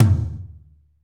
TOM F T L0XL.wav